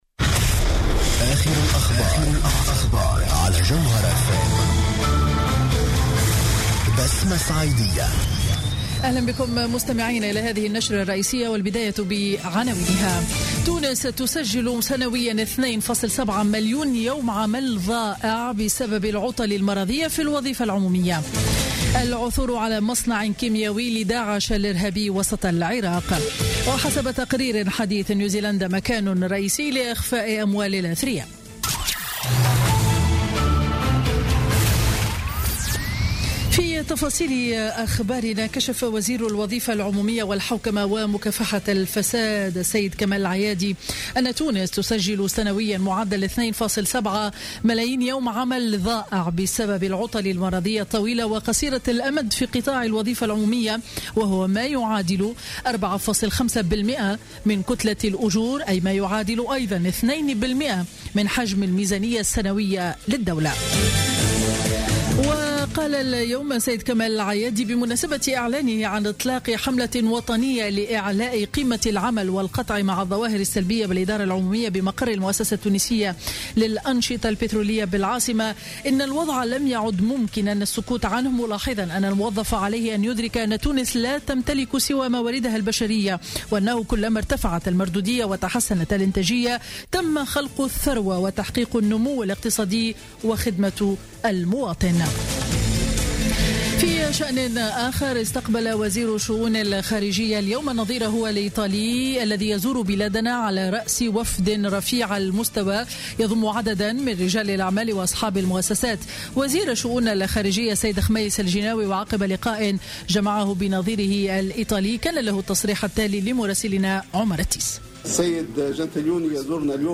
نشرة أخبار منتصف النهار ليوم الإثنين 9 ماي 2016